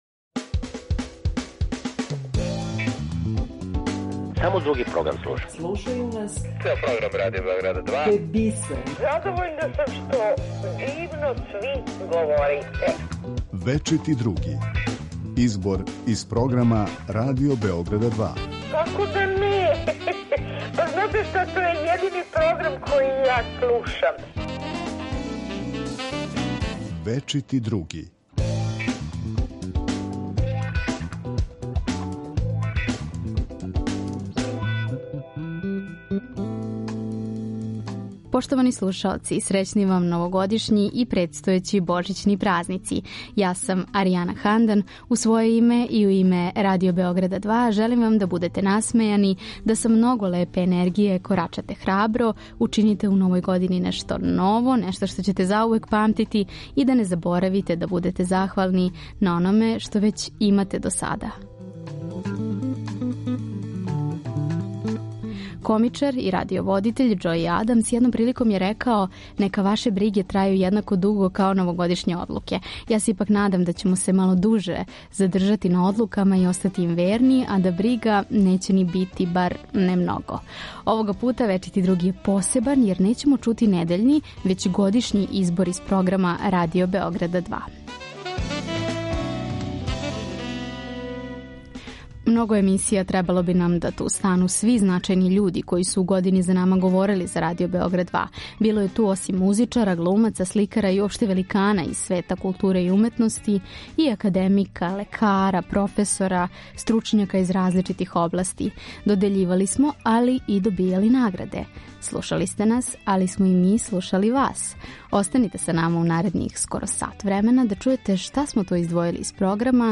У годишњем избору из програма Радио Београда 2 за вас издвајамо делове појединих разговора који су обележили 2022. годину.